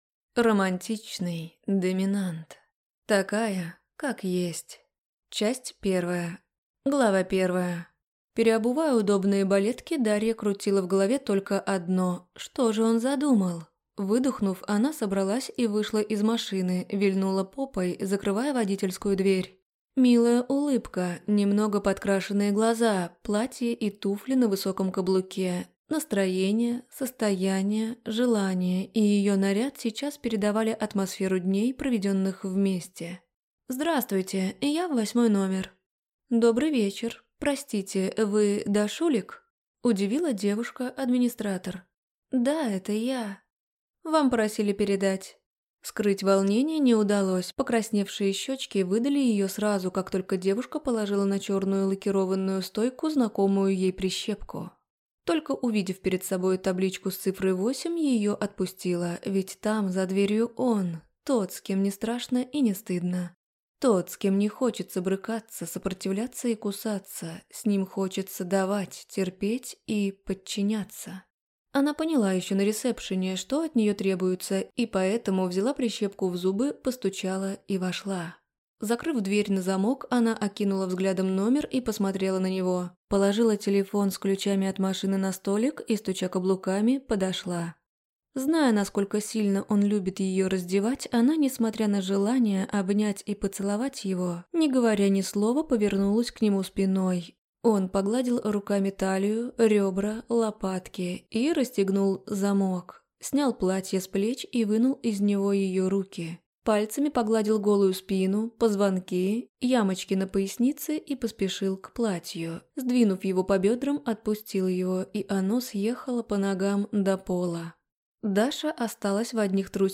Аудиокнига Такая, как есть | Библиотека аудиокниг